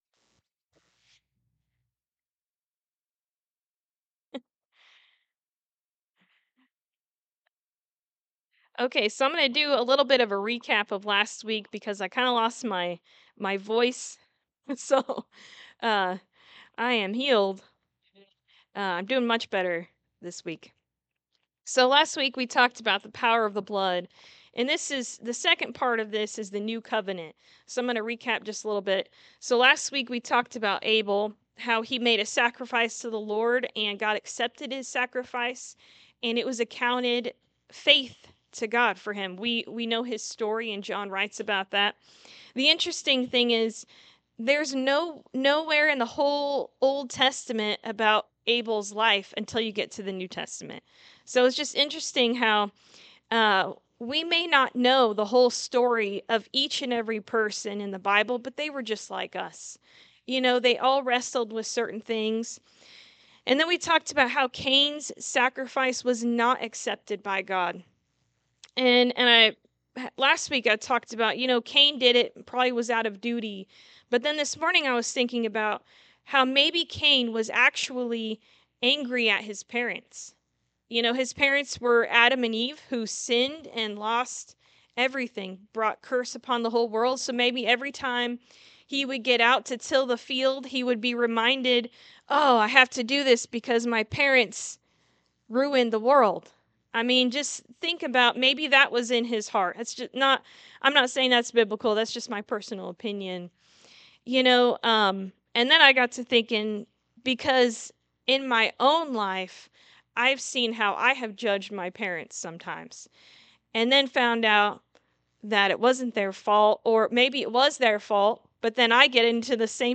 Jeremiah 31:31-34 Service Type: Sunday Morning Service There is resurrection power in the mighty Name of Jesus!